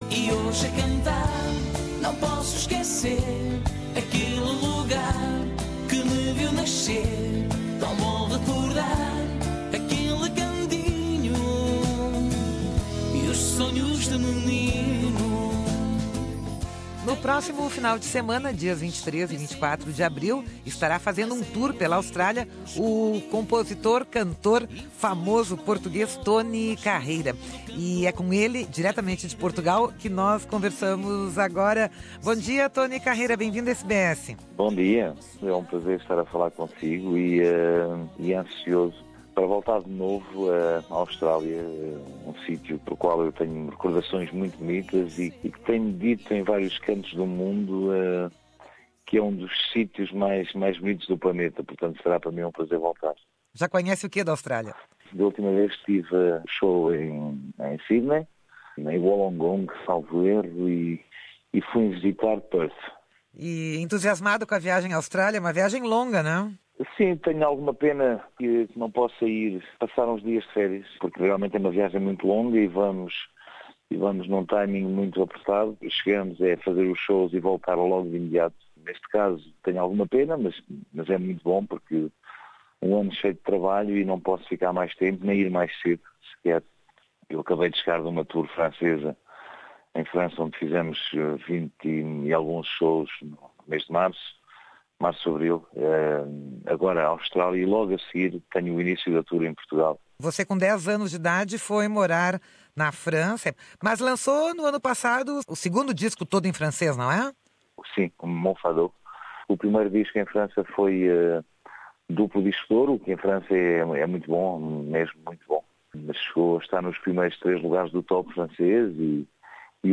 Tony Carreira estará em Melbourne no dia 23 de abril e em Sydney no dia 24, e nós conversamos com ele, direto de Portugal, sobre as suas expectativas para esta turnê australiana.